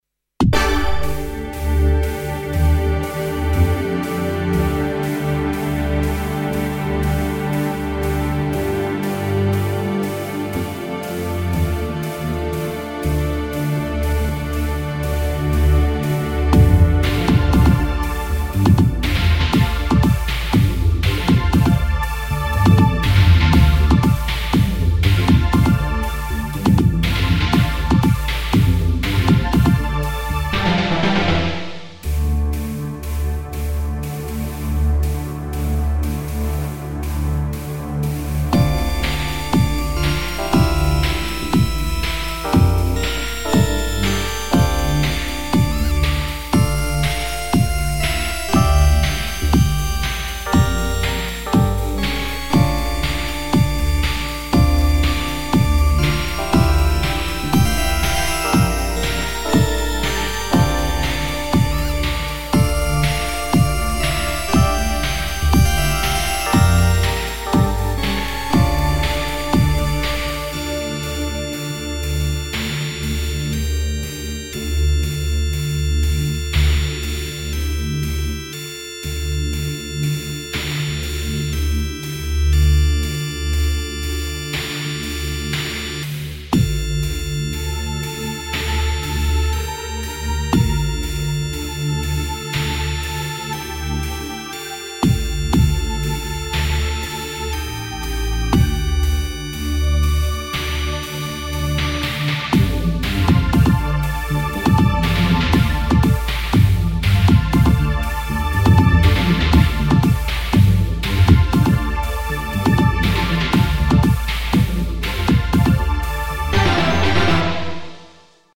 This is a quick effort (2 hours) using only plugins new in Collection V, and Cubase (7.5 Pro) onboard tools. Specifically, the VSTis were Matrix-12, Synclavier, Vox Continental, Solina, Stage-73.